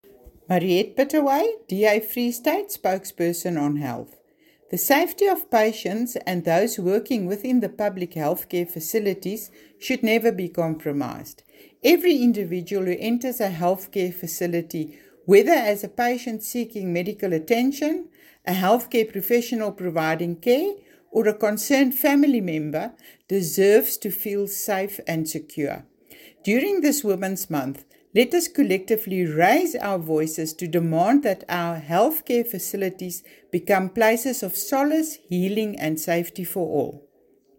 Issued by Mariette Pittaway MPL – DA Free State Spokesperson: Health & Education
Afrikaans soundbites by Mariette Pittaway MPL and